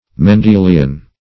Mendelian \Men*de"li*an\, prop. a. [See Mendel's law.]